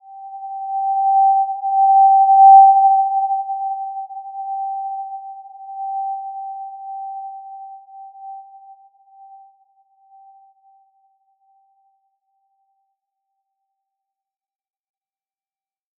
Simple-Glow-G5-p.wav